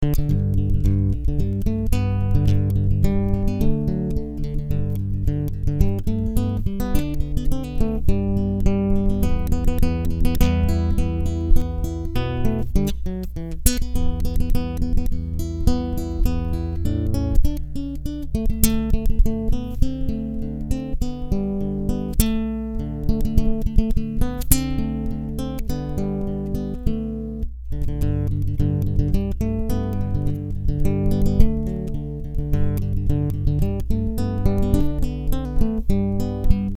Just Acoustic Member Interface
Full Theme: